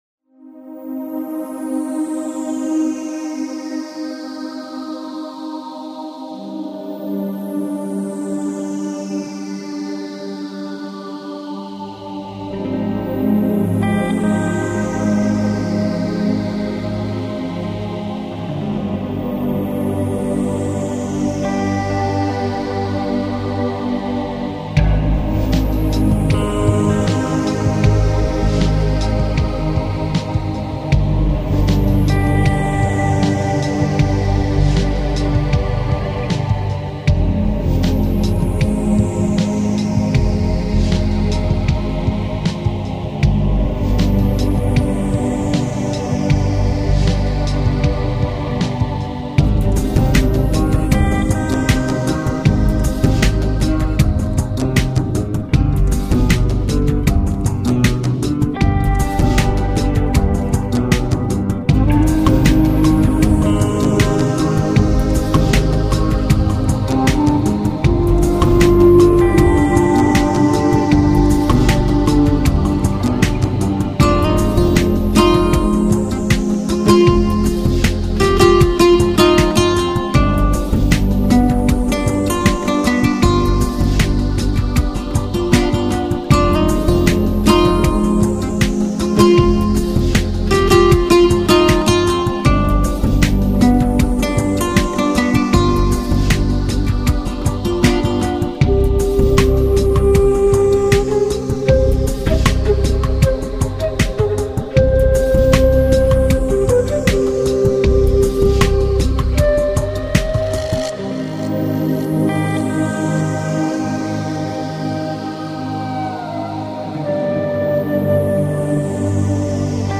álbum conceptual de música de fusión mundial